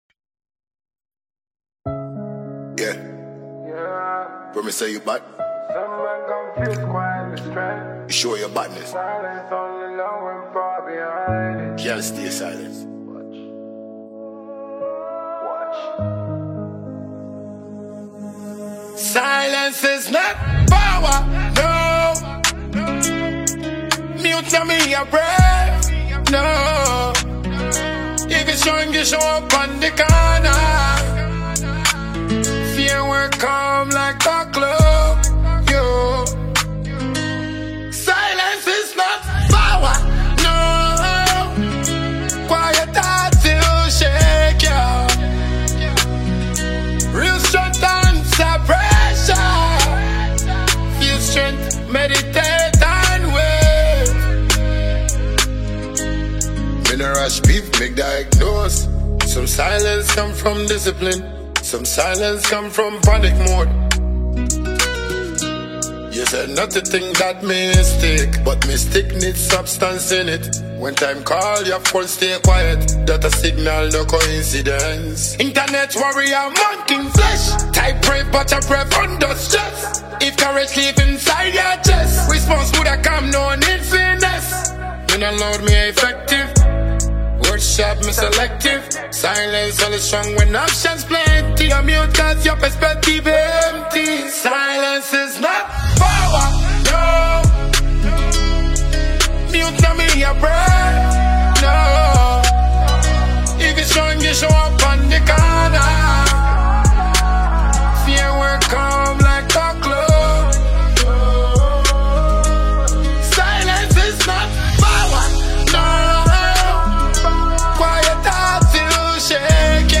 Dancehall
With hard-hitting lyrics and his signature fearless delivery
blends a strong instrumental with raw emotion
feels personal, reflective, and confrontational all at once